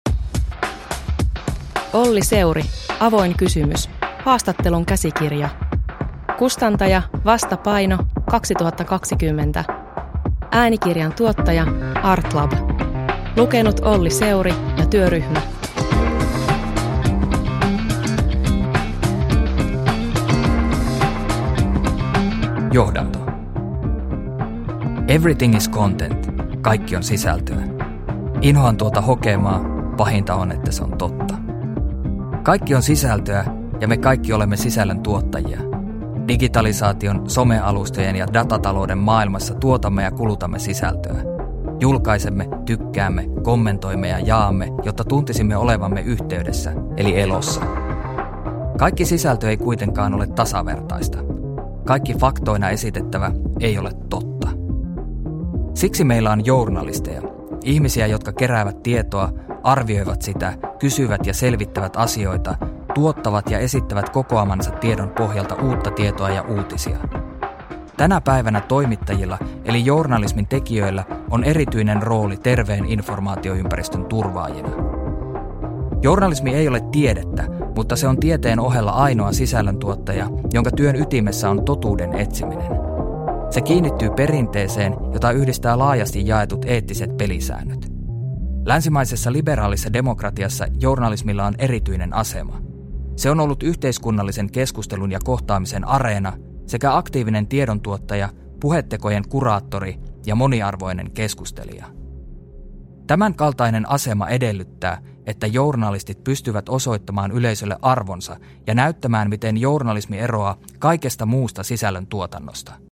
Avoin kysymys – Ljudbok – Laddas ner